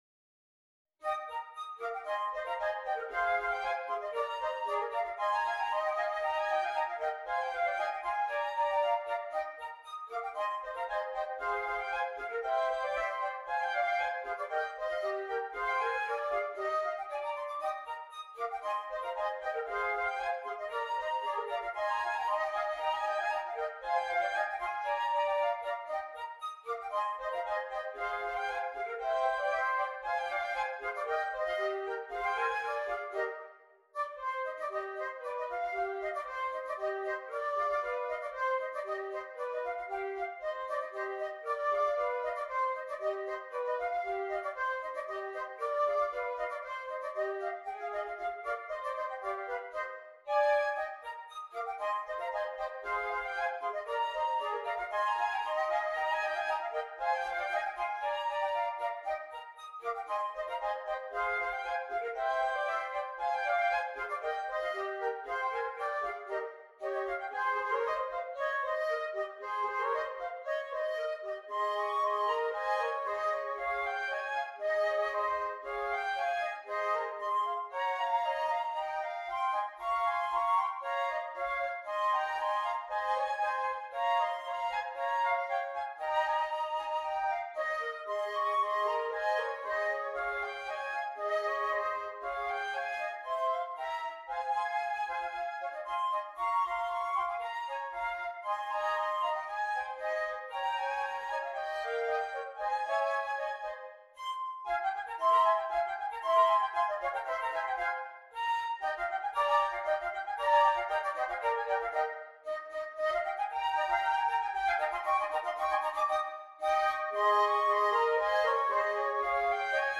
3 Flutes